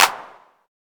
Hand Clap Sound A# Key 21.wav
Royality free clap single shot tuned to the A# note. Loudest frequency: 3384Hz
hand-clap-sound-a-sharp-key-21-hPd.ogg